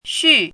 [ xù ]
xu4.mp3